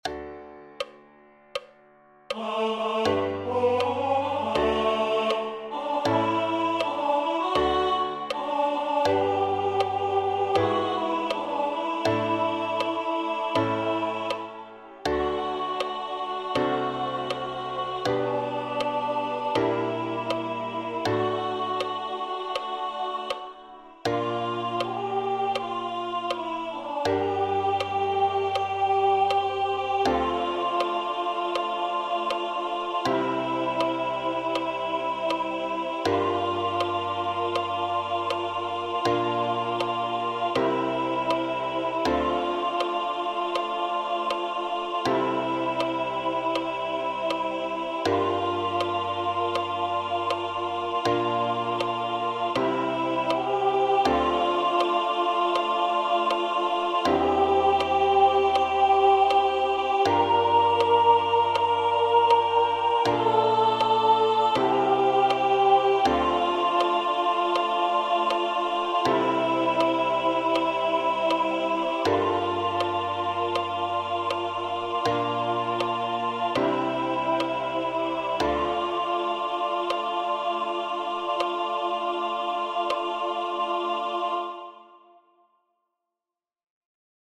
Sopran,
den-stora-dagen-sopran-ljudfil.mp3